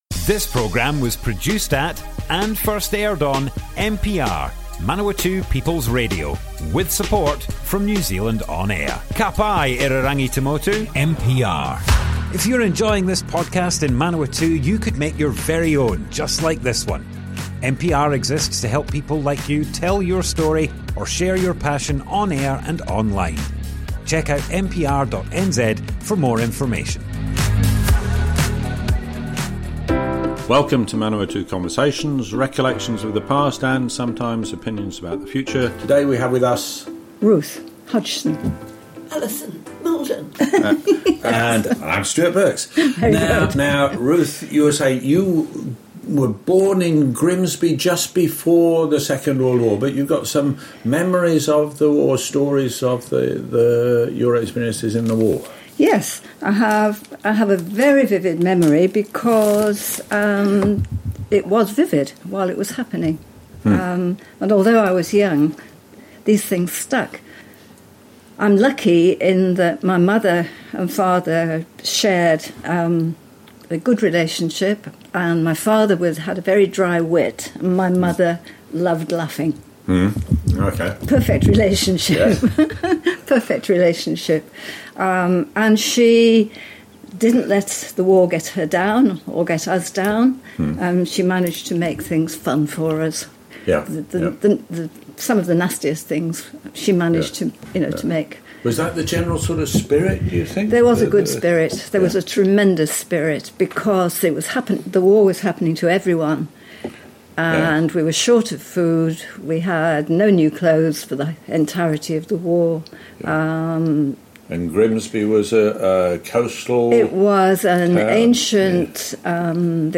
Manawatu Conversations More Info → Description Broadcast on Manawatu People's Radio, 22nd July 2025.
oral history